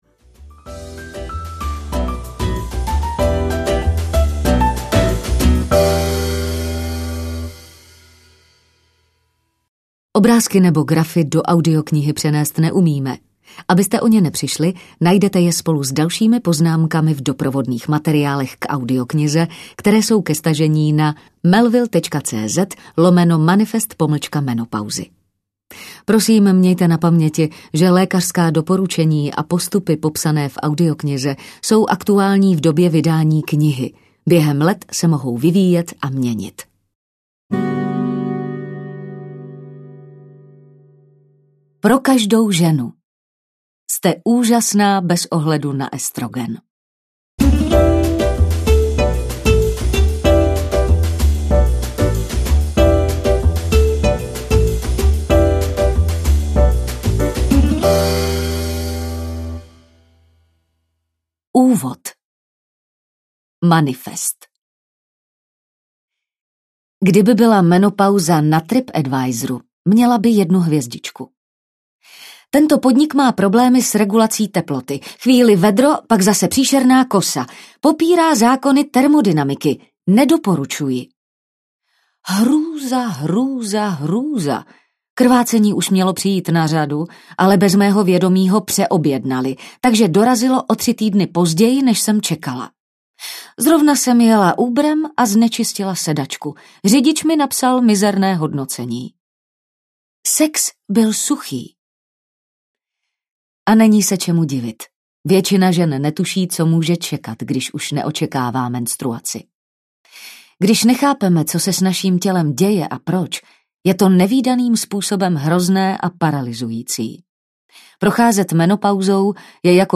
Manifest menopauzy audiokniha
Ukázka z knihy
manifest-menopauzy-audiokniha